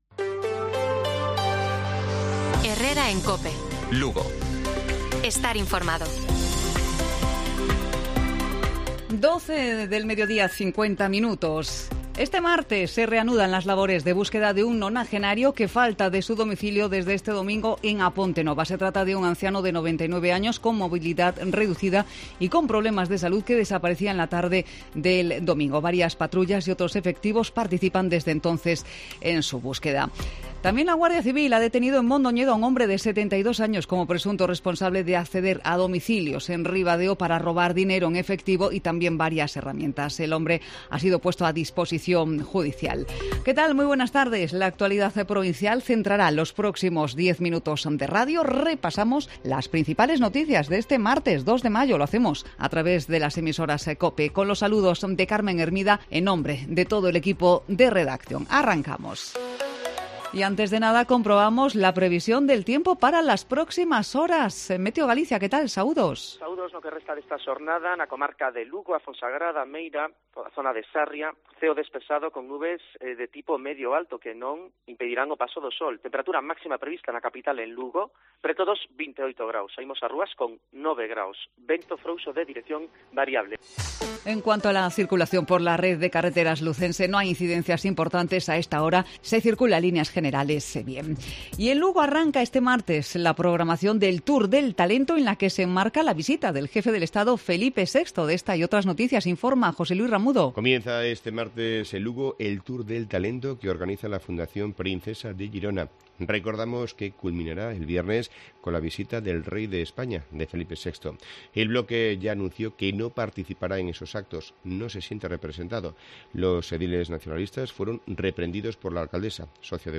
Informativo Provincial de Cope Lugo. 2 de mayo. 12:50 horas